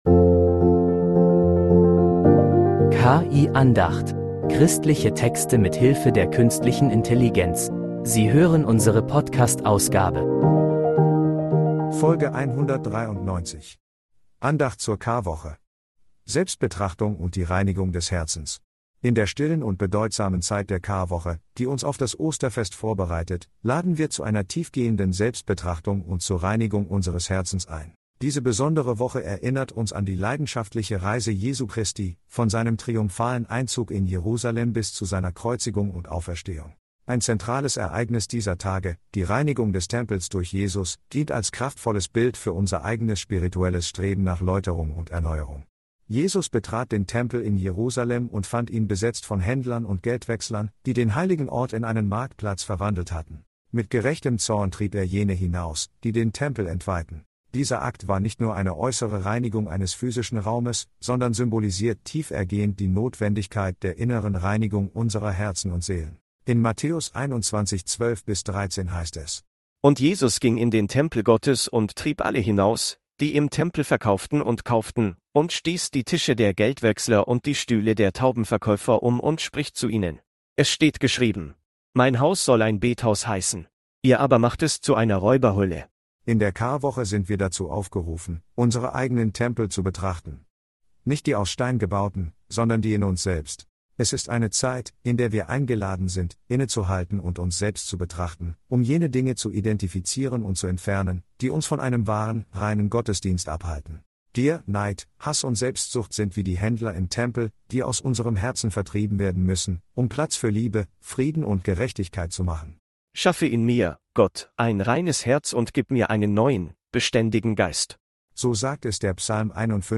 193 – Andacht zur Karwoche: Selbstbetrachtung und die Reinigung des Herzens